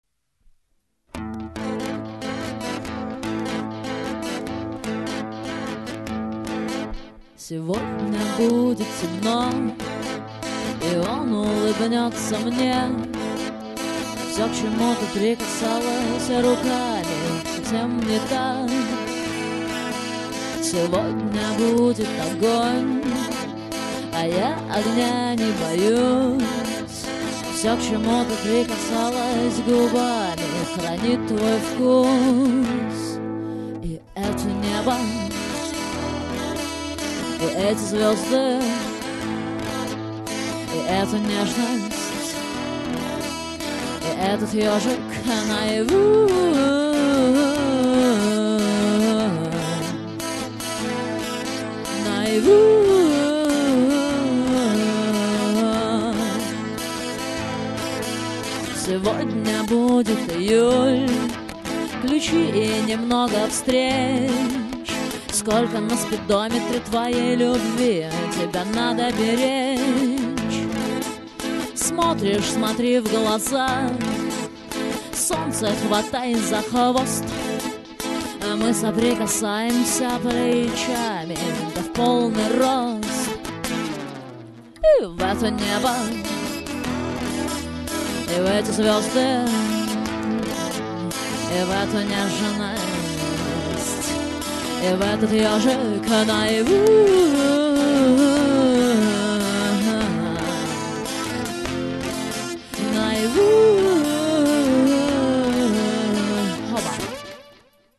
акуст.демо